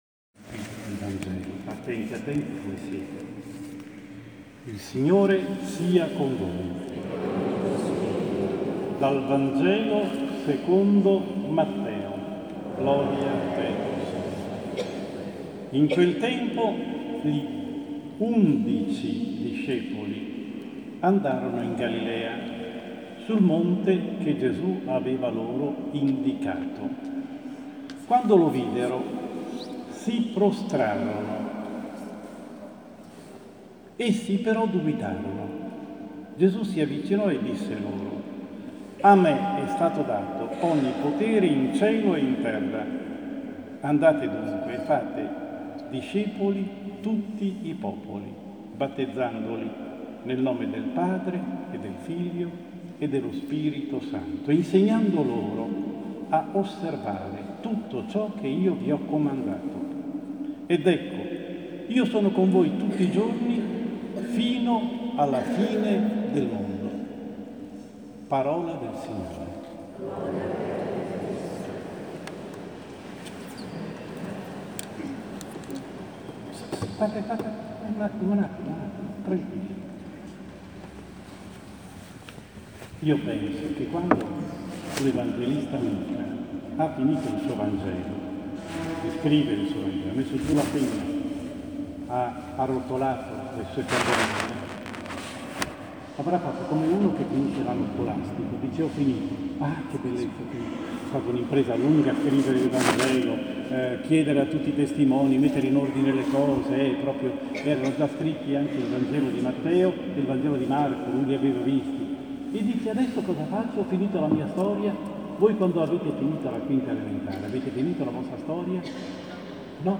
Omelia-Messa-Ascensione-2017.m4a